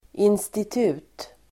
Uttal: [instit'u:t]